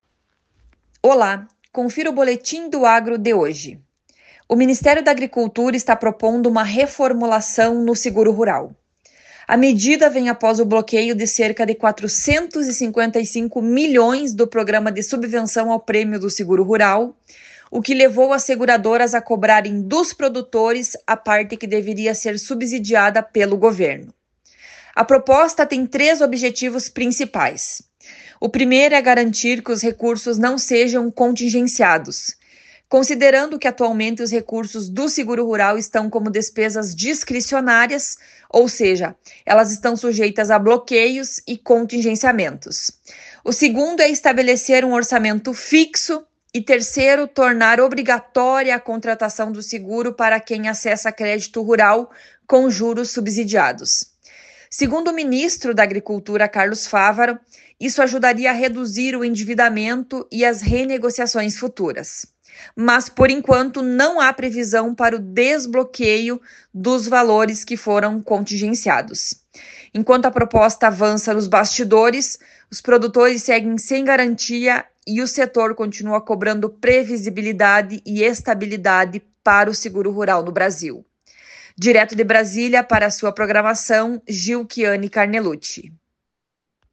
Boletim do Agro de hoje